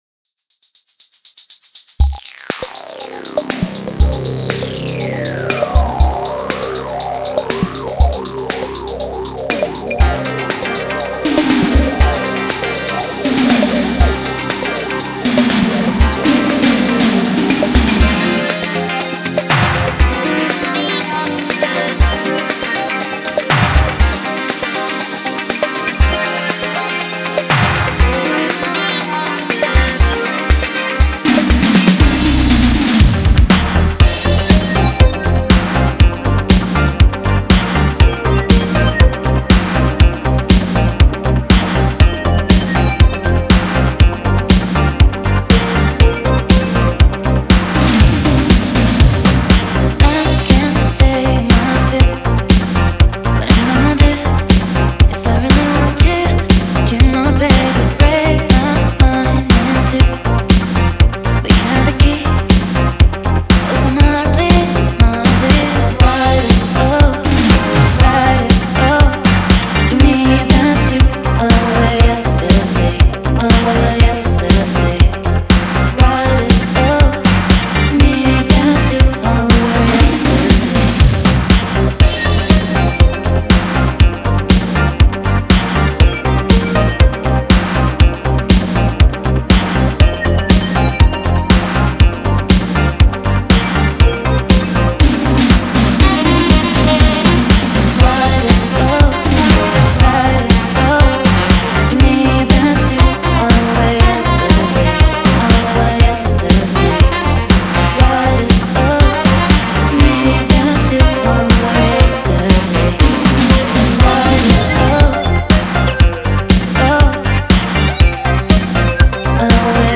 Ритм-секция с саксом из 80-х, вокал с пэдами — трансовые какие-то, мужик в конце — из наших трэп-дней) У тебя в миксе вокал как-то подтарчивает не по уставу)